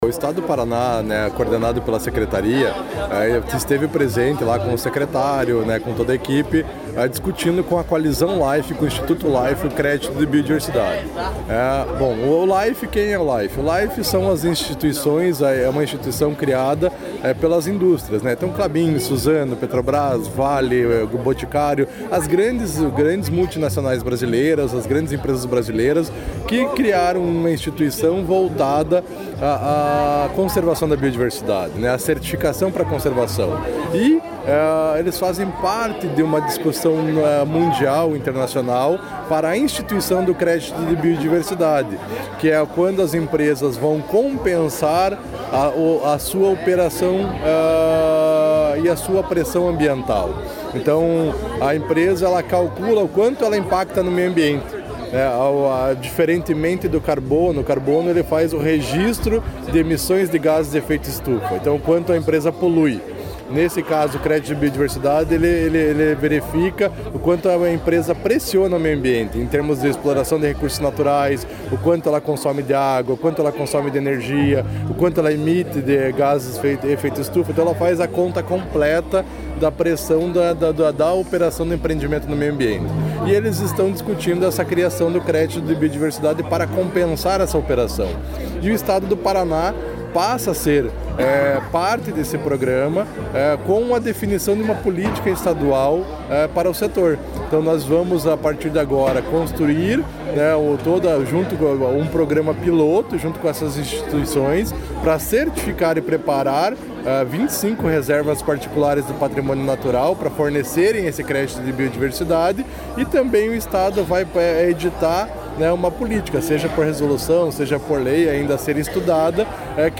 Sonora do diretor de Políticas Ambientais da Sedest, Rafael Andreguetto, sobre a política de créditos de biodiversidade